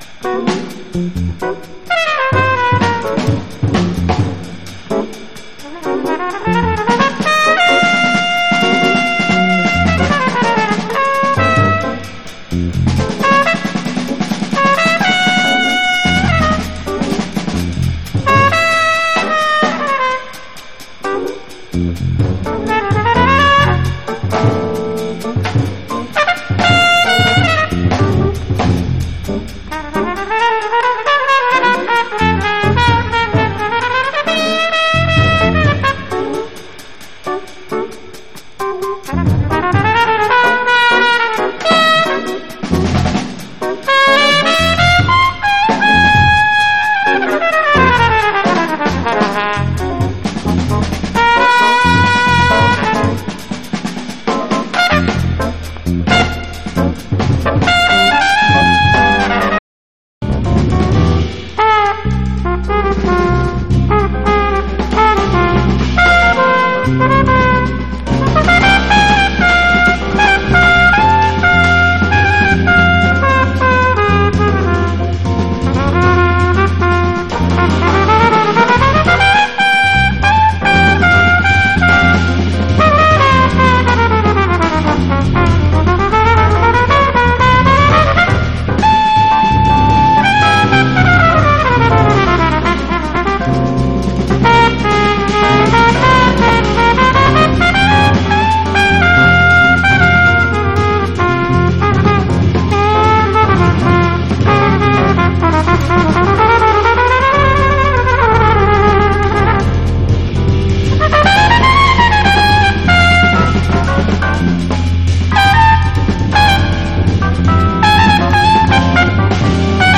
JAZZ / MAIN STREAM / JAZZ ROCK